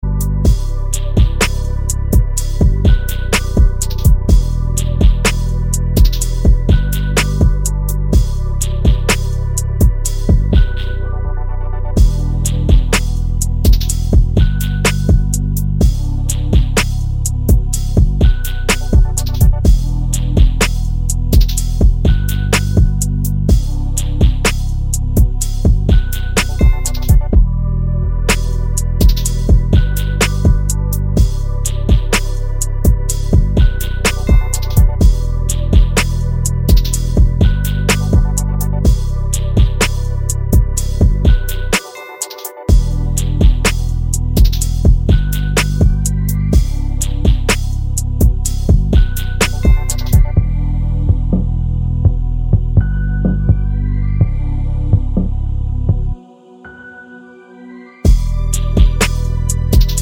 no Backing Vocals Finnish 4:04 Buy £1.50